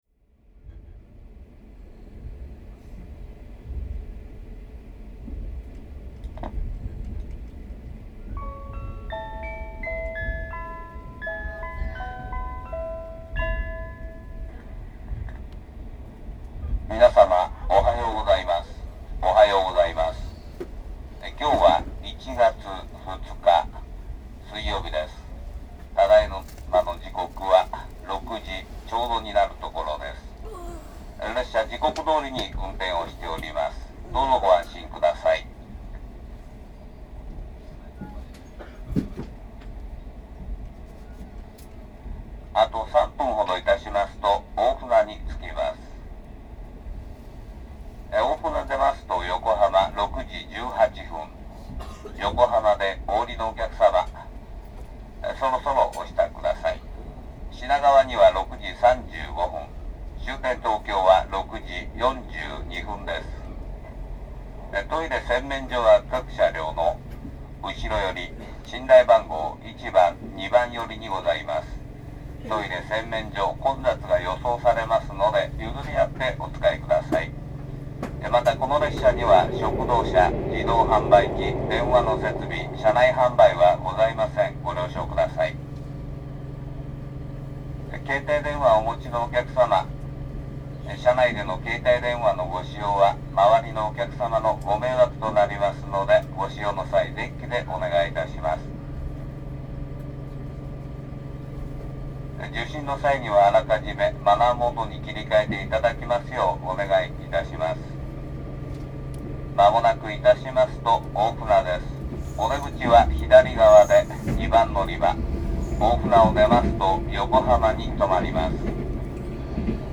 おはよう放送（102レ「銀河」　オハネフ25 132車内）